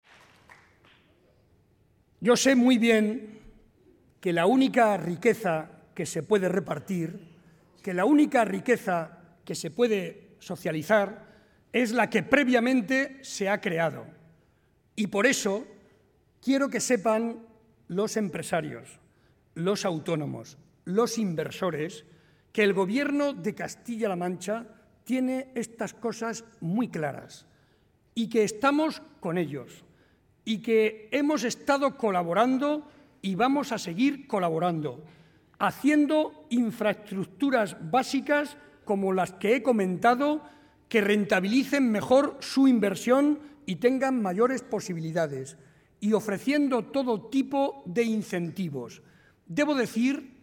El presidente de Castilla-La Mancha y candidato a la reelección, José María Barreda, fue recibido por unas 1.100 personas en el Teatro Quijano de Ciudad Real a gritos de «presidente, presidente», donde advirtió de la importancia de las próximas elecciones, ya que, dijo, «nos jugamos el futuro de nuestro sistema sanitario, la educación de nuestros hijos y el bienestar de nuestros mayores».